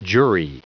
Prononciation du mot jury en anglais (fichier audio)
Prononciation du mot : jury